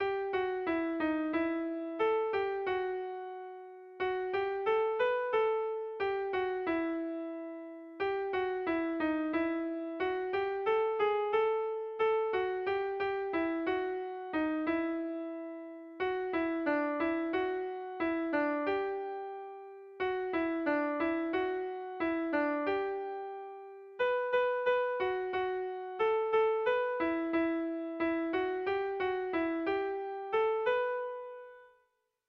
Haurrentzakoa
ABDEEF